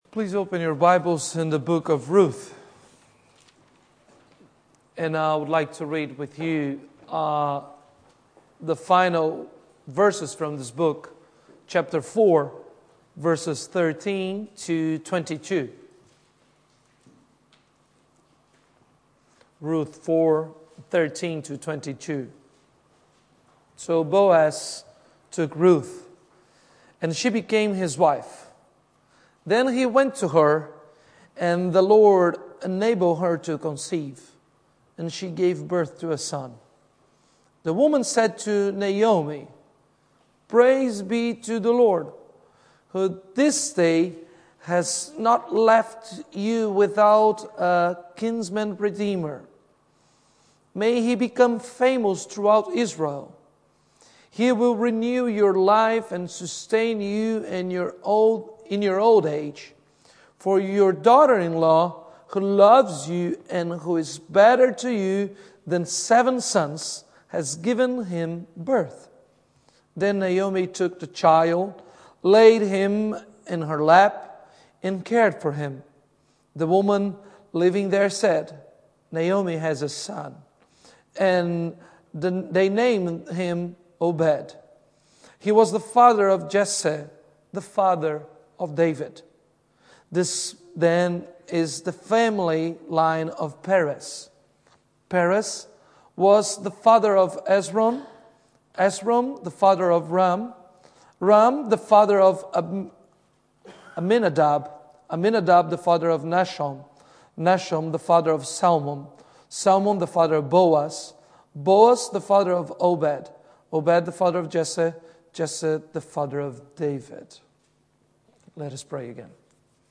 This is a blogsite created to enable playing and Podcasting Christ Church sermons.